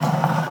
wood_m3.wav